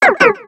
Cri de Polarhume dans Pokémon X et Y.